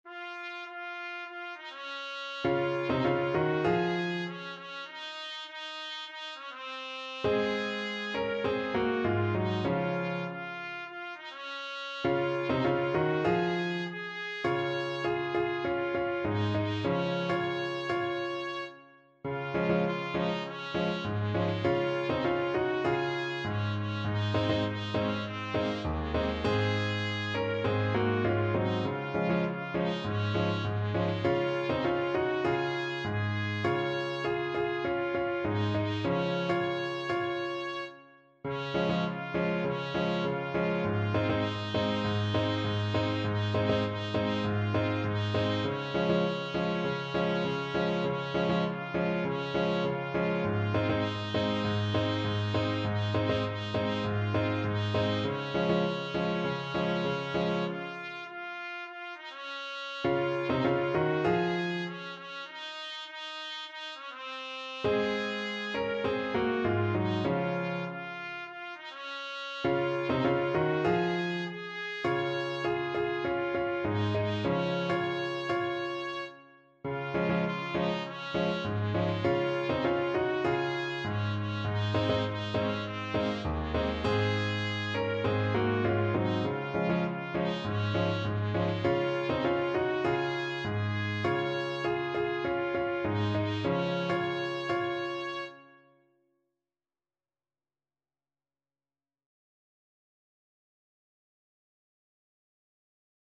Trumpet
Db major (Sounding Pitch) Eb major (Trumpet in Bb) (View more Db major Music for Trumpet )
4/4 (View more 4/4 Music)
Moderato
Traditional (View more Traditional Trumpet Music)
world (View more world Trumpet Music)
Caribbean Music for Trumpet